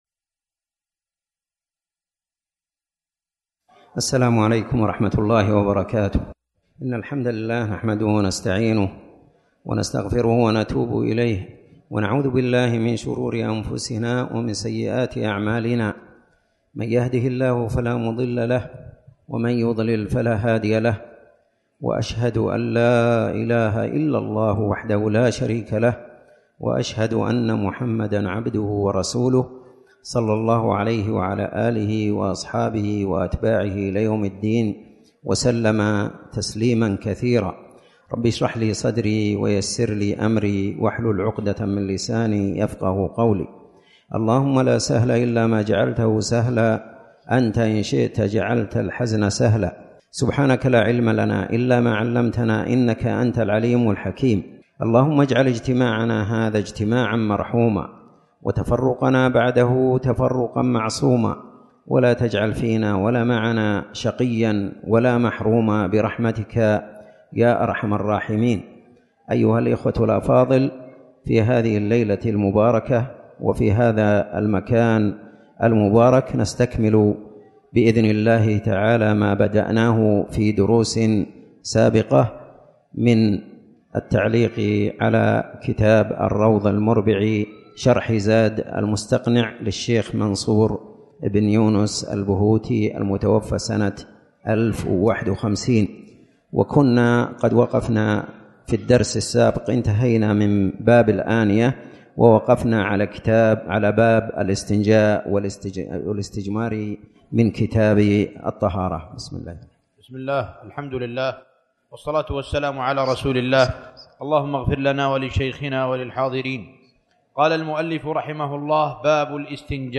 تاريخ النشر ١٩ جمادى الأولى ١٤٣٩ هـ المكان: المسجد الحرام الشيخ